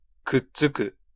Prononciation
Variantes orthographiques (obsolète) adhærer Synonymes coller Prononciation France: IPA: /a.de.ʁe/ Le mot recherché trouvé avec ces langues de source: français Traduction Échantillon audio 1.